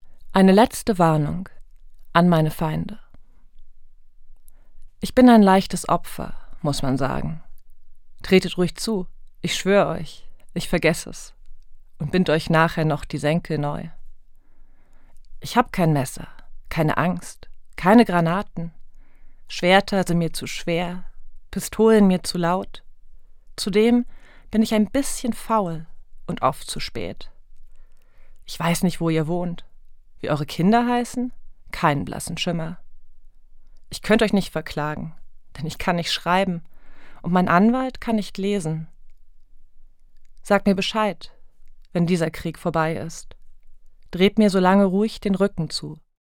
Das radio3-Gedicht der Woche: Dichter von heute lesen radiophone Lyrik.